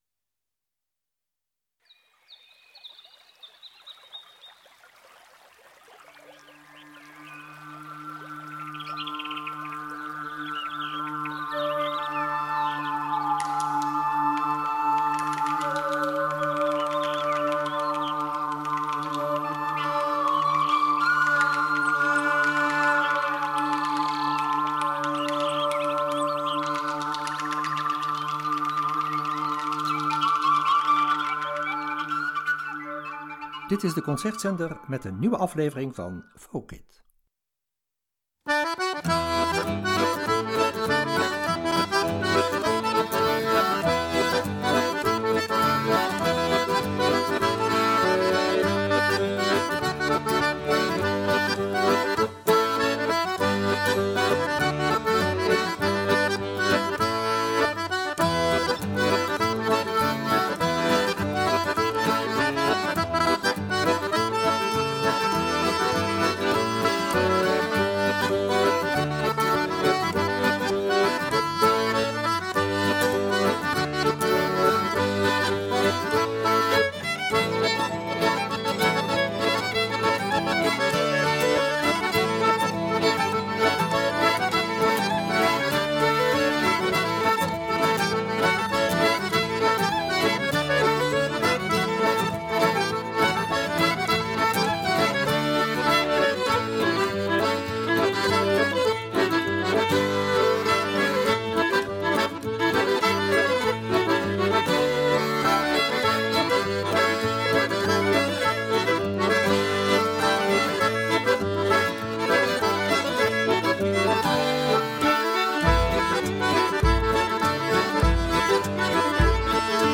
drie folk groepen die dansmuziek spelen
viool, accordeon, zang
doedelzak, banjo
acoustische bas
percussie en voeten
Franse dansfolk-groep
zang, hurdy-gurdy